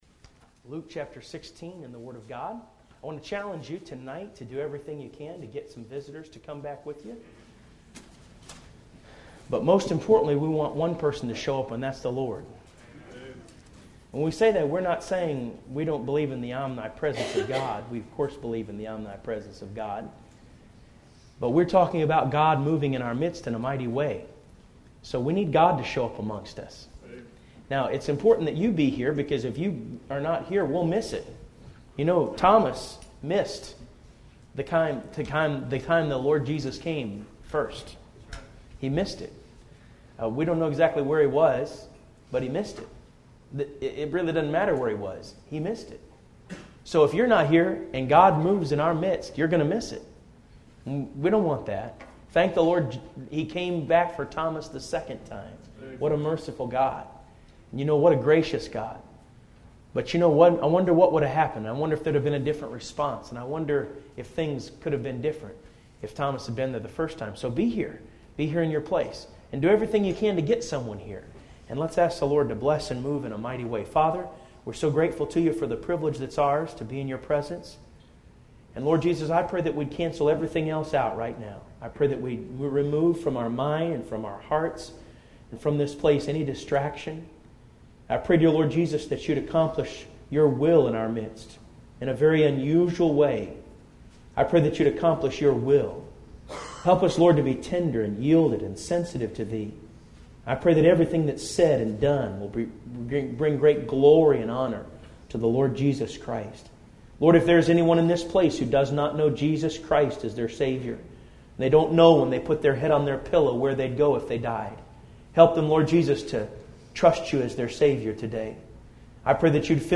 A Glimpse of Hell – (Spring Revival) – Bible Baptist Church